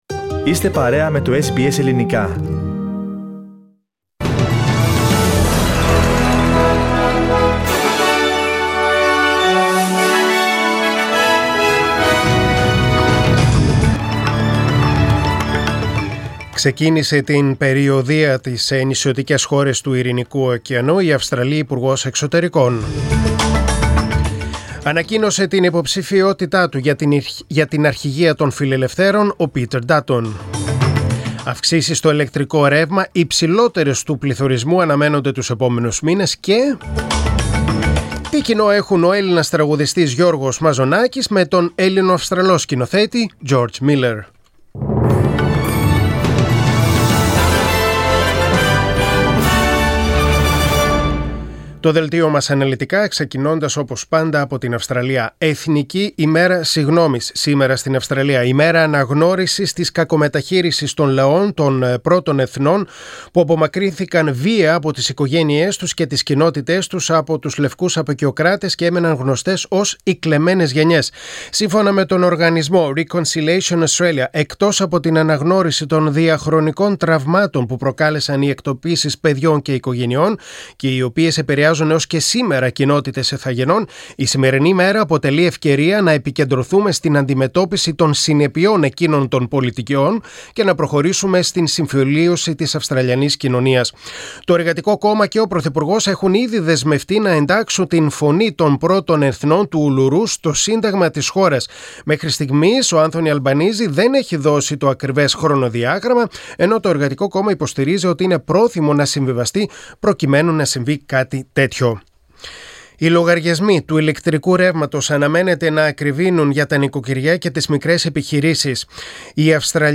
Δελτίο Ειδήσεων: Πέμπτη 26.5.2022